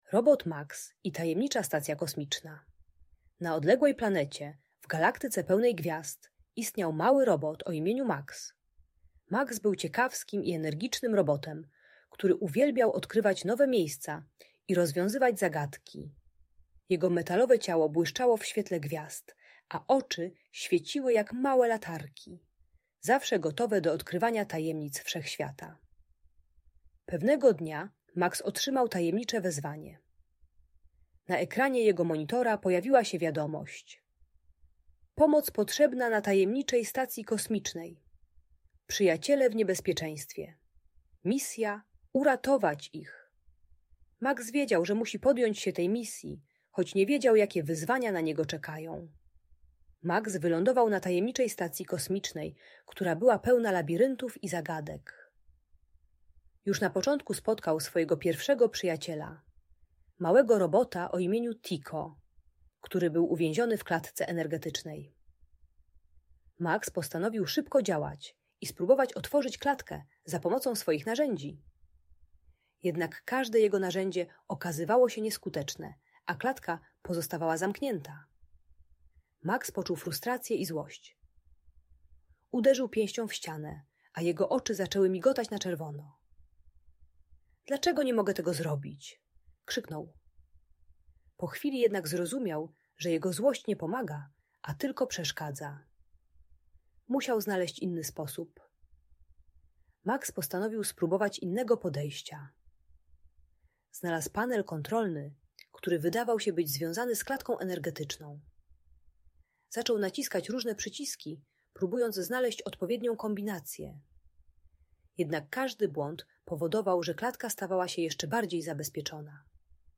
Robot Max i Tajemnicza Stacja Kosmiczna - Audiobajka